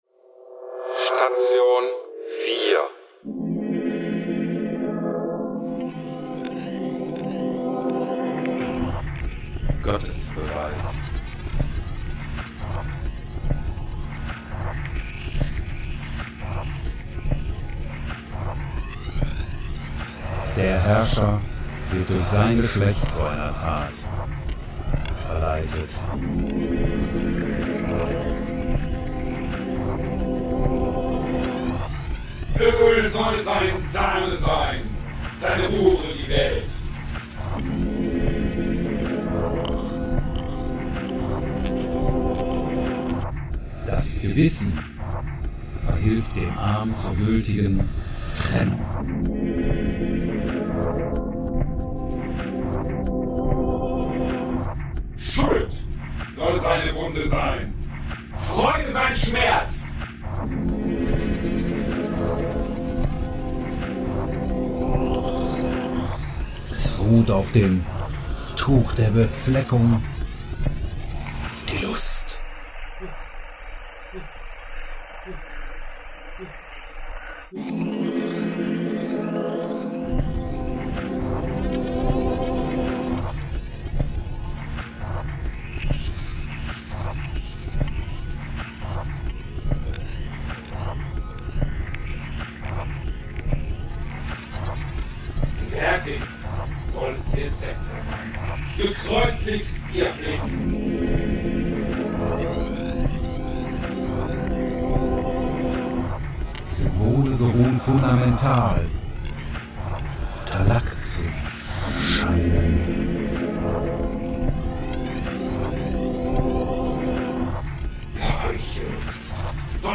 Expressive Klangwelten